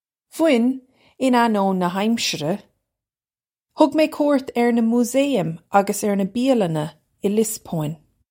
Vwin, in an-oh-in nuh hamshirra. Hug may koo-irt urr nuh moosay-im uggus urr nuh bee-a-lunna ih Liss-po-in.
This is an approximate phonetic pronunciation of the phrase.